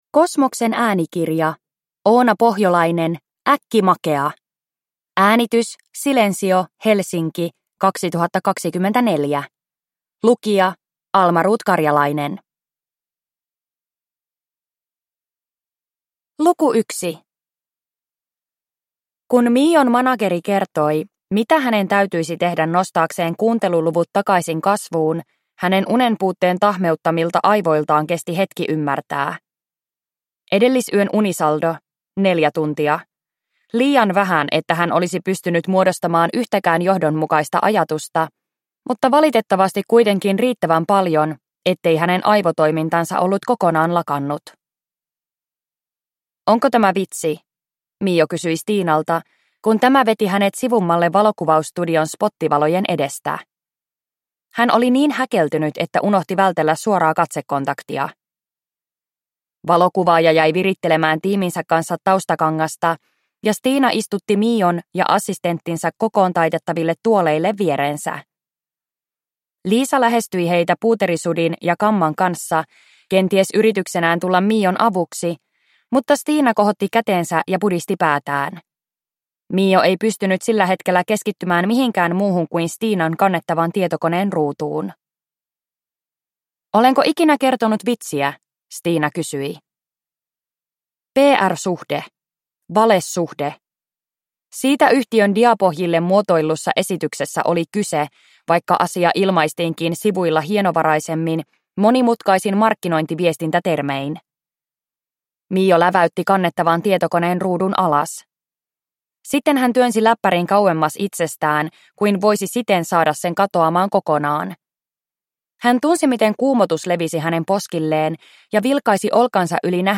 Äkkimakea – Ljudbok